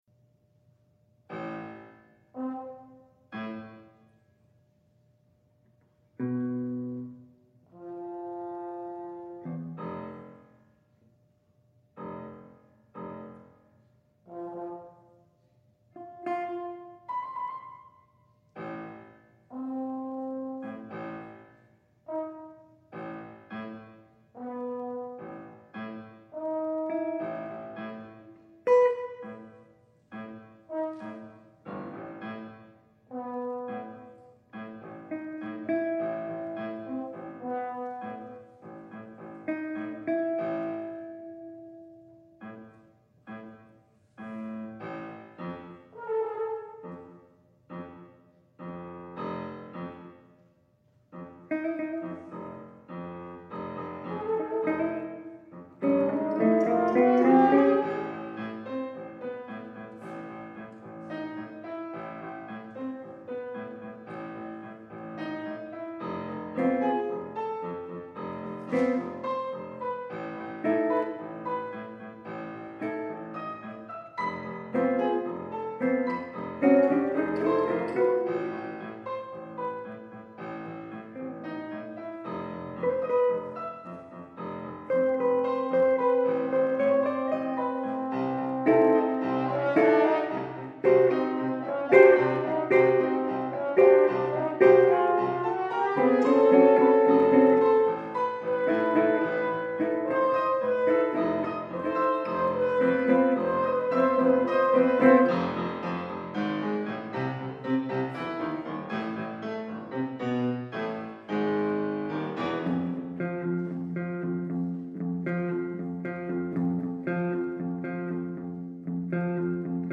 Horn
I arrived at the main idea of an awkward “popish” groove that incorporated 7/8 measures as a way for all the instruments to meet in the middle, so to speak. The other sections are meant to violently oppose this A section in a lot of ways and give each instrument a chance to at least touch on some of its traditional qualities and techniques.
The piece is full of interesting rhythmic and melodic ideas, which are often shared and passed among the three players. Although it is not an “easy” piece, the pop/rock elements and unusual combination of instruments make it both appealing and accessible for general audiences.
Electric Guitar, Horn and Piano
Unfortunately, because of some scheduling difficulties we ended up not performing the piece on the March recital, but we were able to premiere it later in the semester on a new music concert.